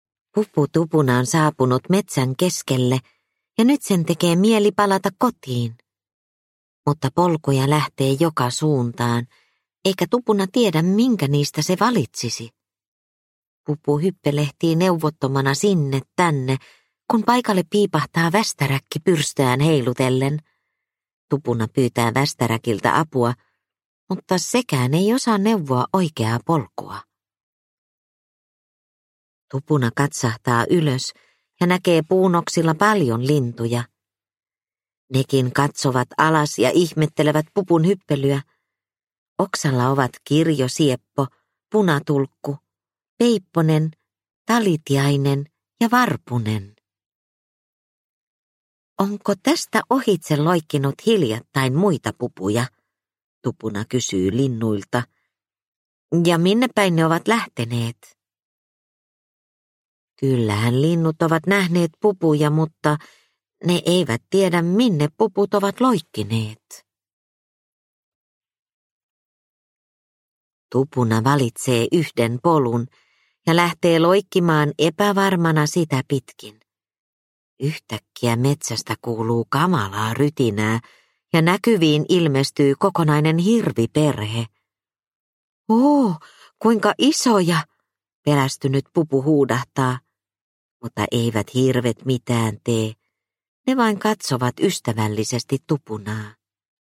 Pupu Tupunan paluu – Ljudbok – Laddas ner